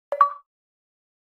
Download XBOX Achievement Unlocked sound button
xbox-achievement-unlocked.mp3